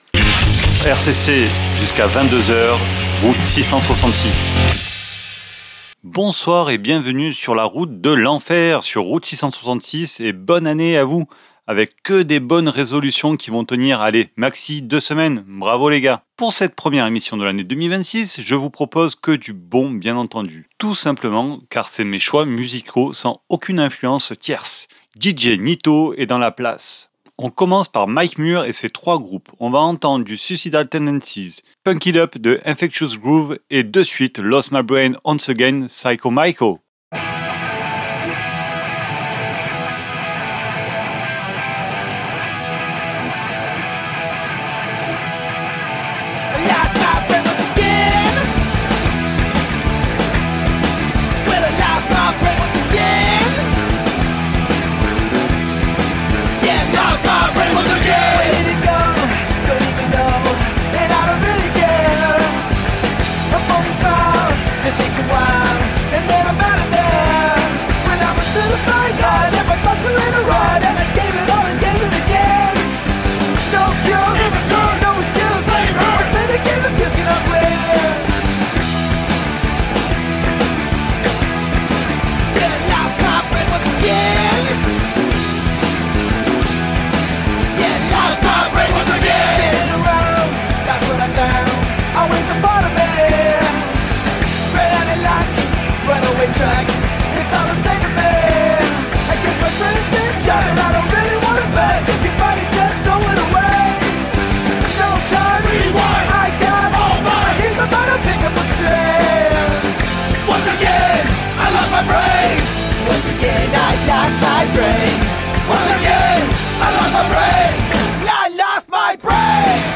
Route 666 Emission du mercredi 7 JANVIER 2026 votre dose de hard rock métal sur RCC !